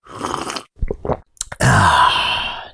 ahh.ogg